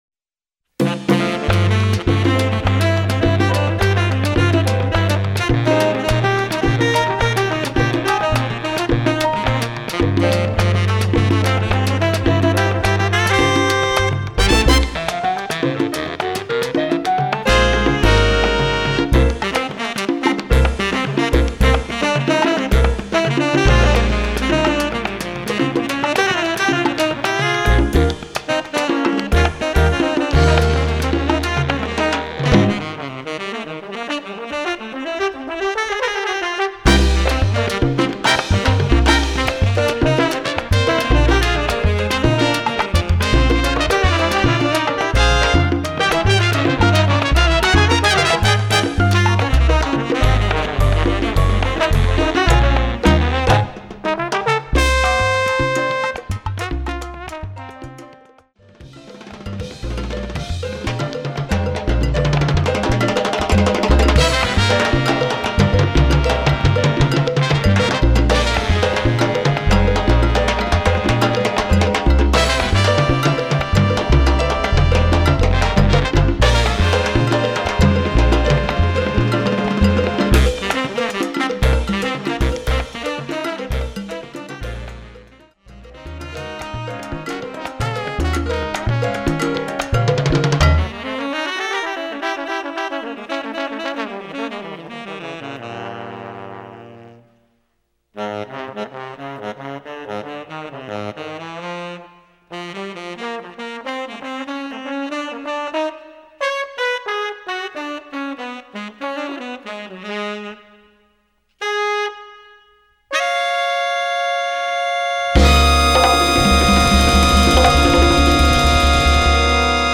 Category: big band
Style: Latin jazz mambo
Solos: tenor, trombone
Instrumentation: big band (4-4-5, rhythm (4)